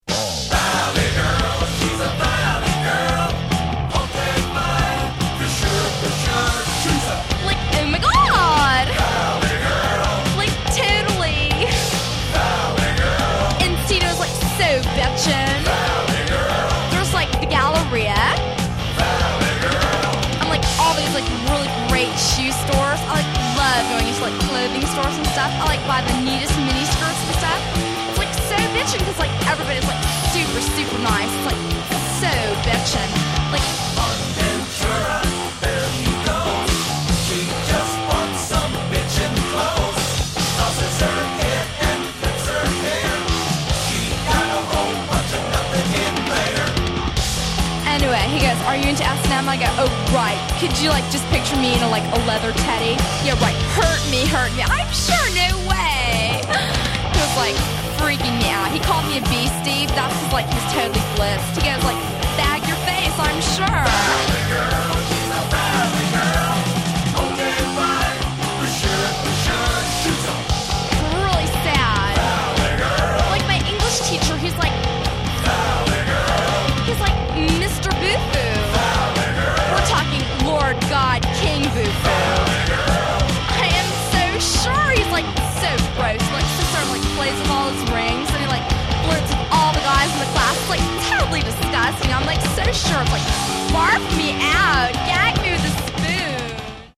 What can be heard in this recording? Genre: Novelty